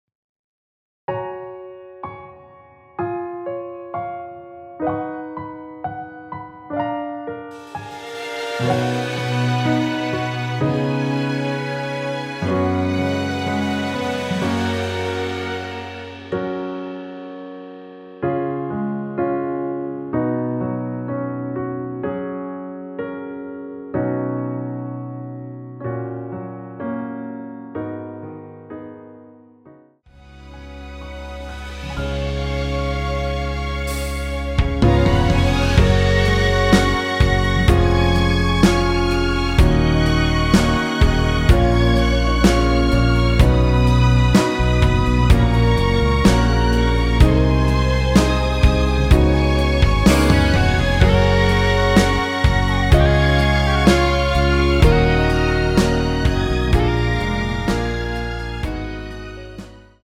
원키에서(+5)올린 MR입니다.
F#
앞부분30초, 뒷부분30초씩 편집해서 올려 드리고 있습니다.